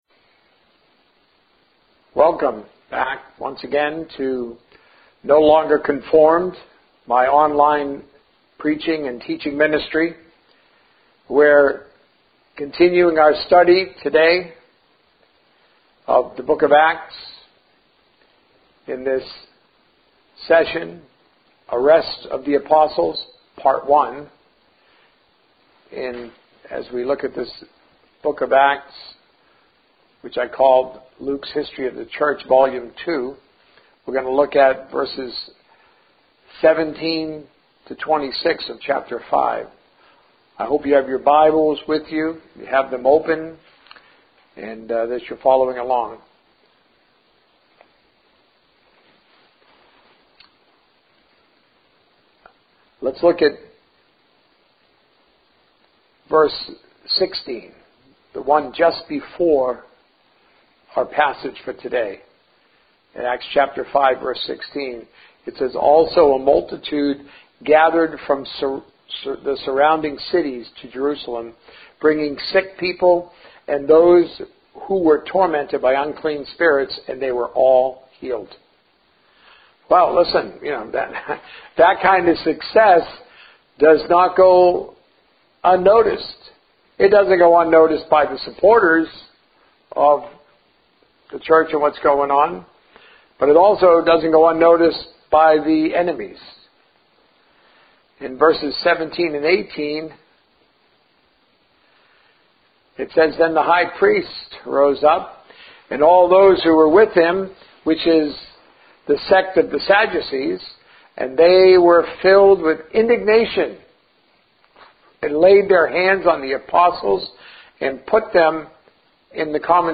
A message from the series "The Source of Success."